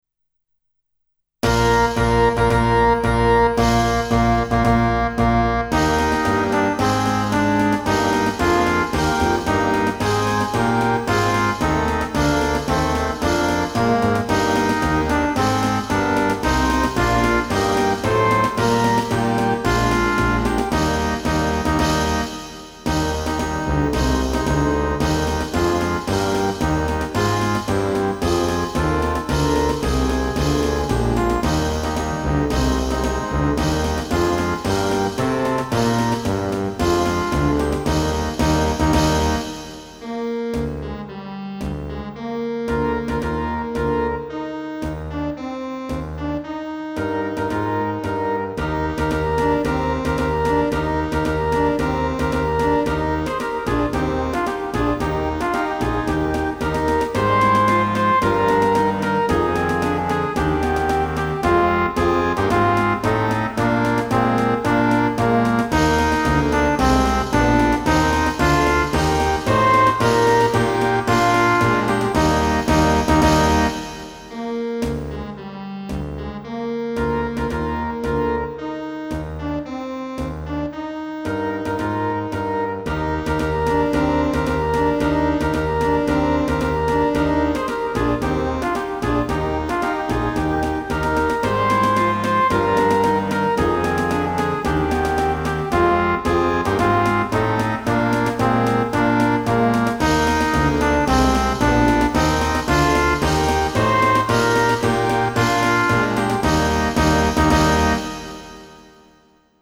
◎　デモ音源（ＭＰ３ファイル）
ウェールズからの大行進　(デジタル音源)